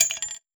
metahunt/weapon_ammo_drop_11.wav at master
weapon_ammo_drop_11.wav